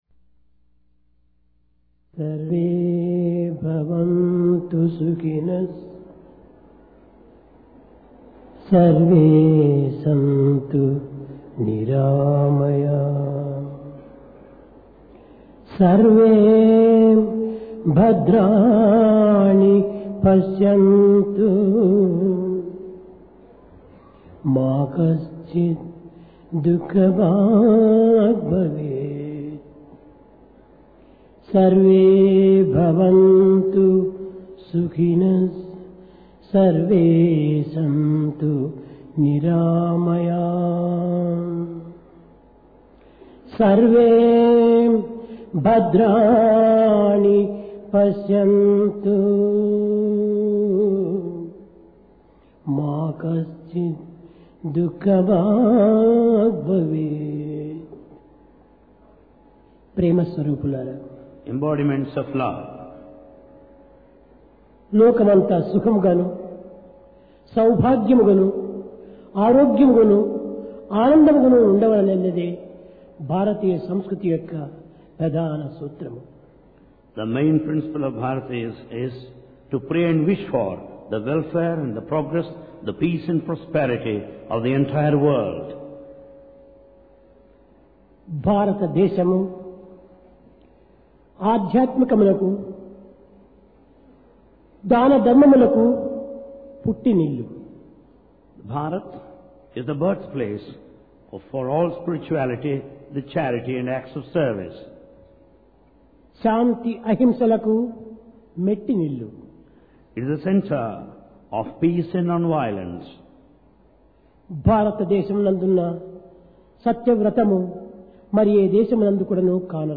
Occasion: Divine Discourse Place: Prashanti Nilayam Love - The Gift Of God To Man